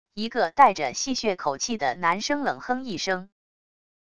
一个带着戏谑口气的男声冷哼一声wav音频